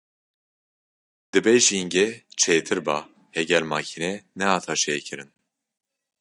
Wekî (IPA) tê bilêvkirin
/mɑːkiːˈnɛ/